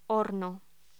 Locución: Horno
voz